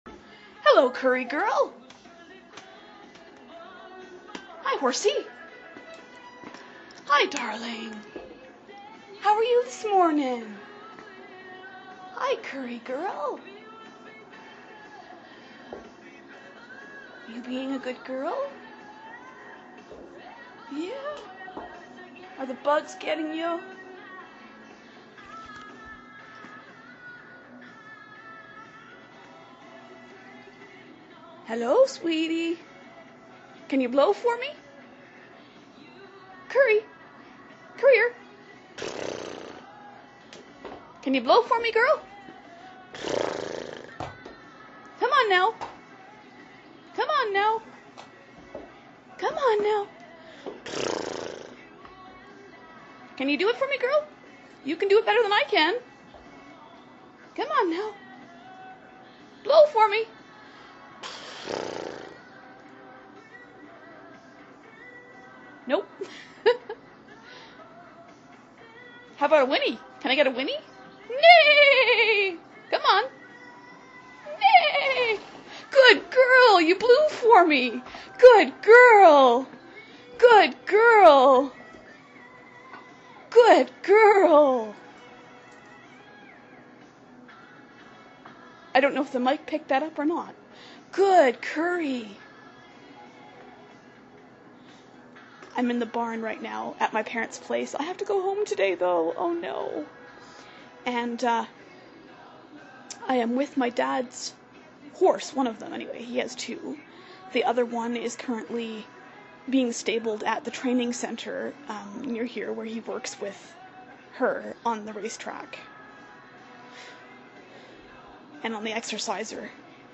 I wish to goodness I'd had my stereo mic when I recorded this. Hopefully you can hear her well enough anyway.